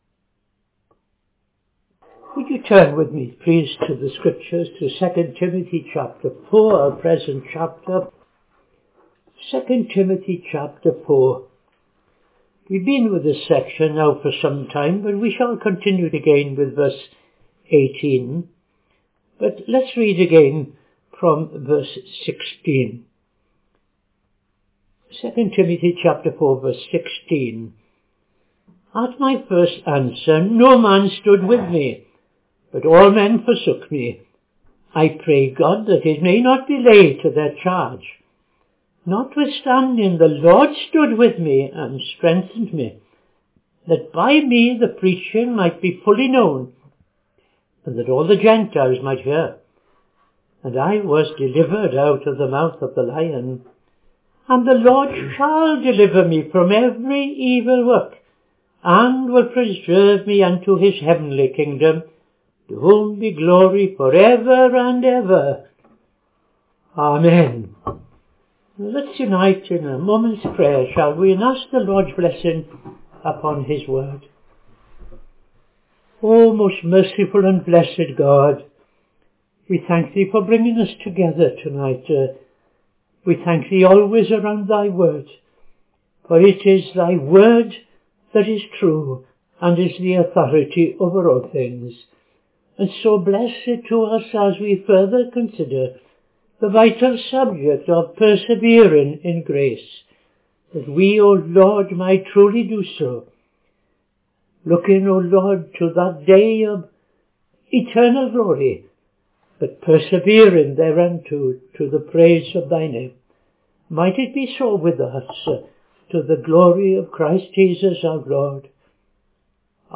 Sermon Part 73 ‘Persevering in Grace’ (2) II Timothy 4:18